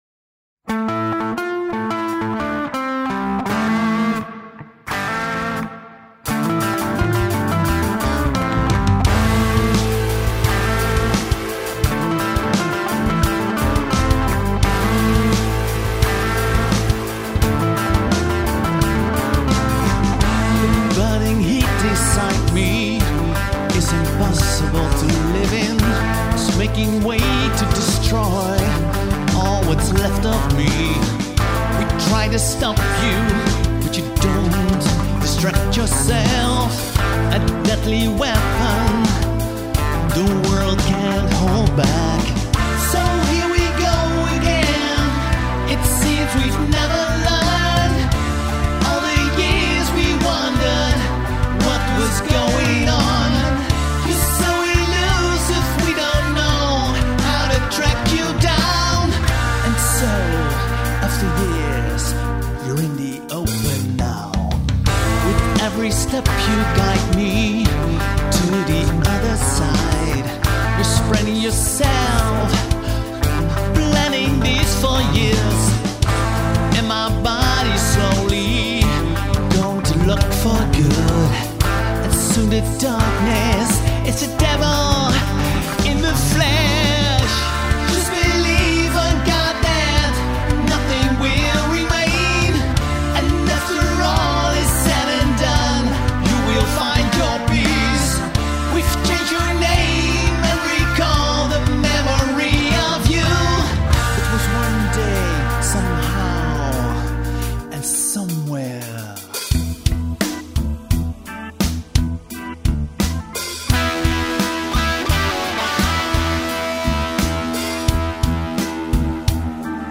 vocals
drums
guitars & bass
keyboards